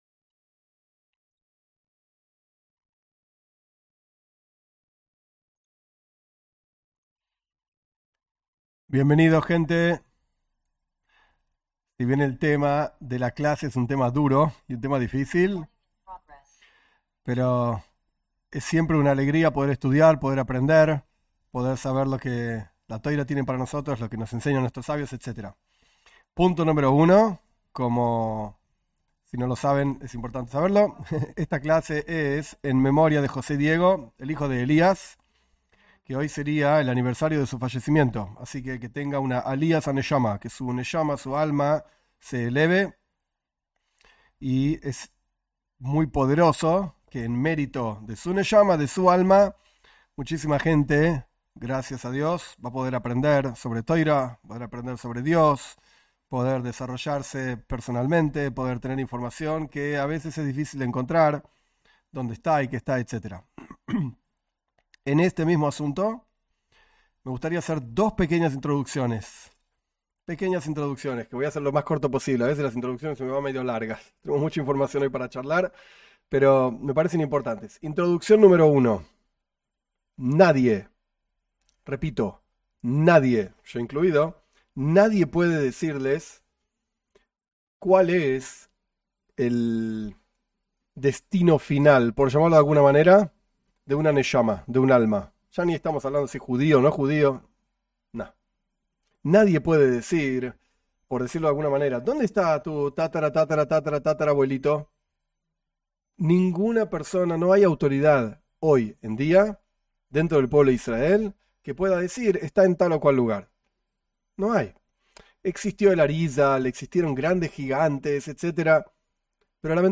En esta clase se explican estos asuntos desde la perspectiva del mensaje del judaísmo para los no judíos (Bnei Noaj).